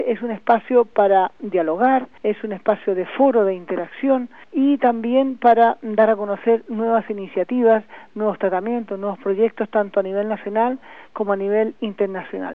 La cadena COPE da la noticia de nuestro weblog durante su informativo del fin de semana: